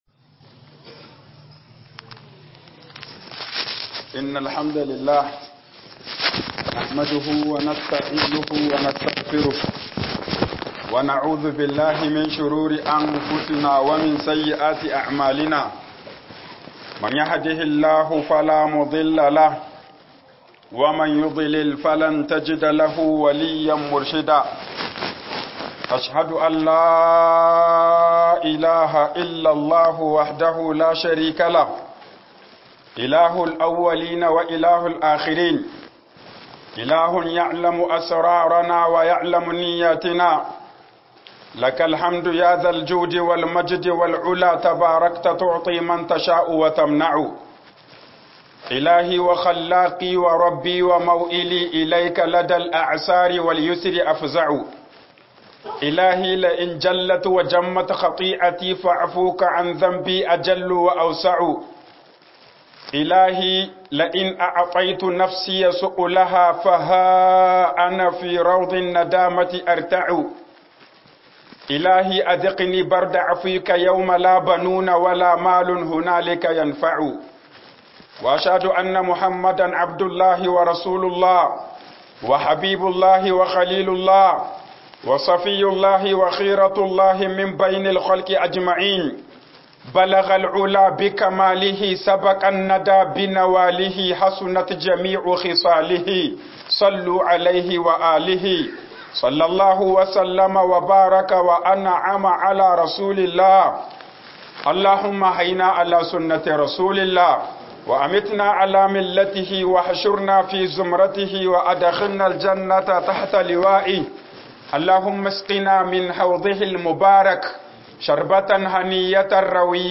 الخطبة في أيام العشر - HUƊUBOBIN JUMA'A